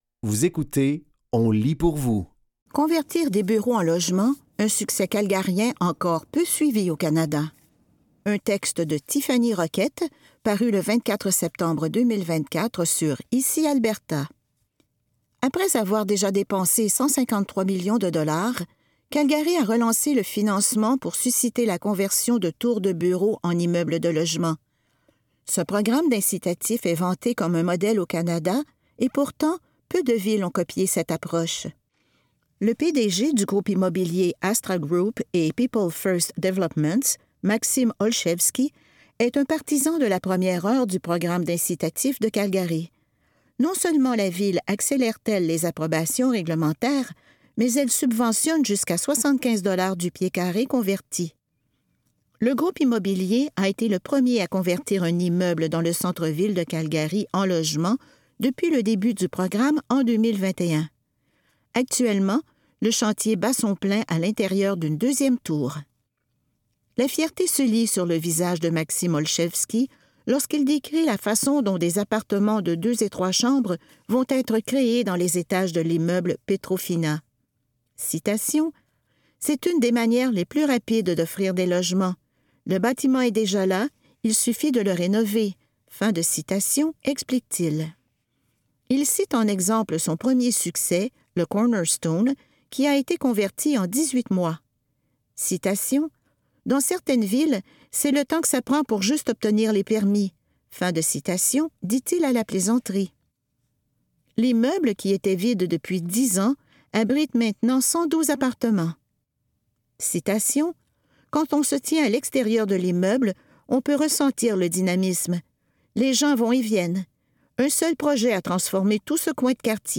Dans cet épisode de On lit pour vous, nous vous offrons une sélection de textes tirés des médias suivants : Métro, La Presse, ICI Grand Nord et Le Devoir.